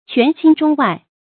權傾中外 注音： ㄑㄨㄢˊ ㄑㄧㄥ ㄓㄨㄙ ㄨㄞˋ 讀音讀法： 意思解釋： 權勢壓倒朝廷內外。